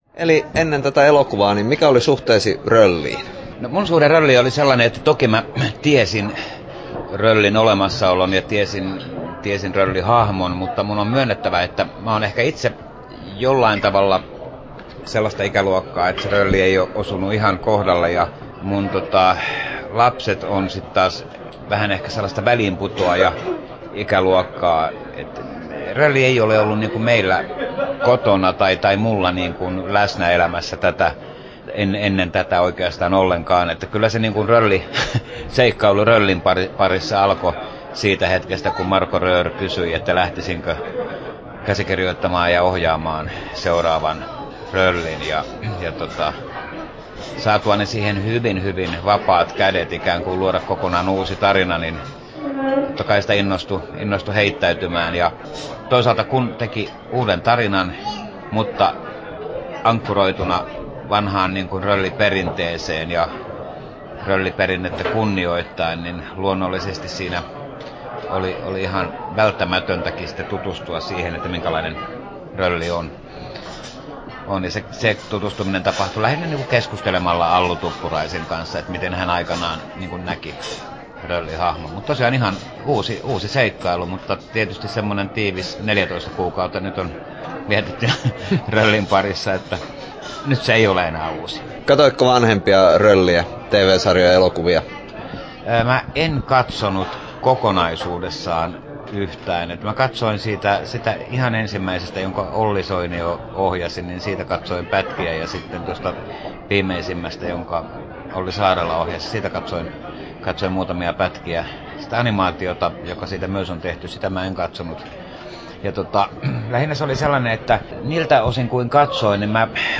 7'49" Tallennettu: 27.1.2013, Turku Toimittaja